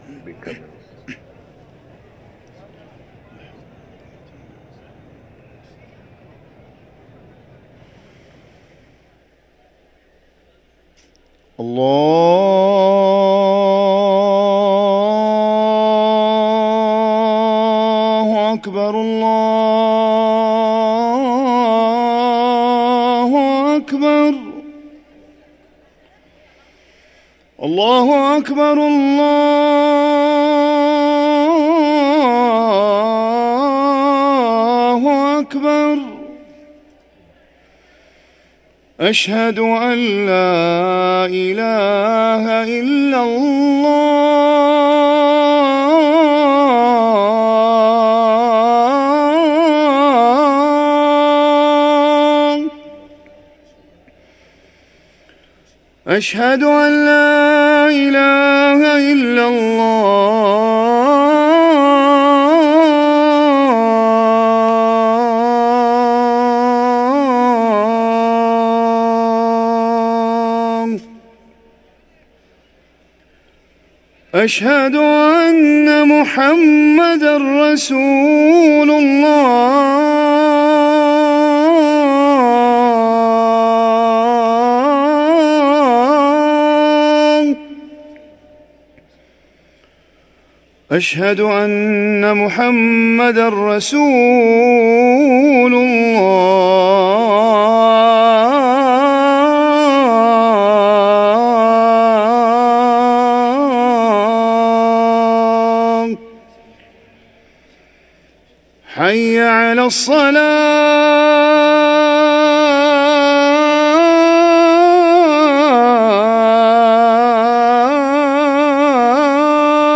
أذان المغرب
ركن الأذان